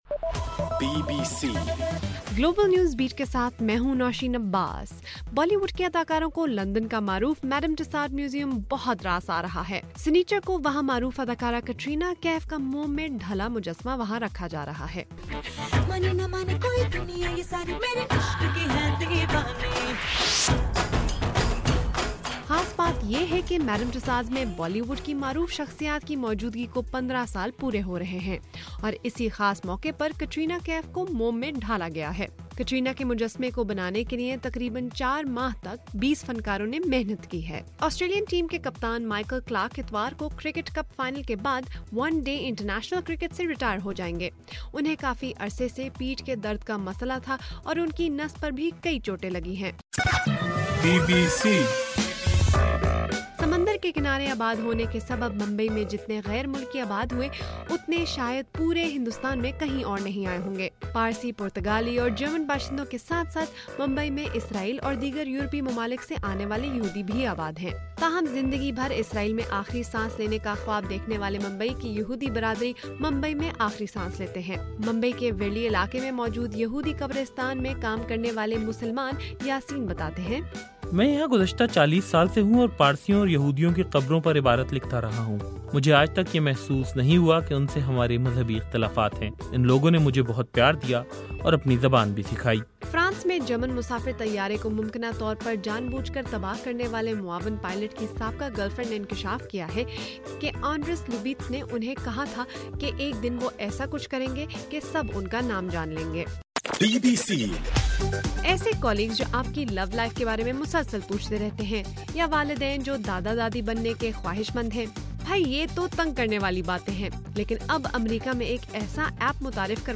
مارچ 28: رات 12 بجے کا گلوبل نیوز بیٹ بُلیٹن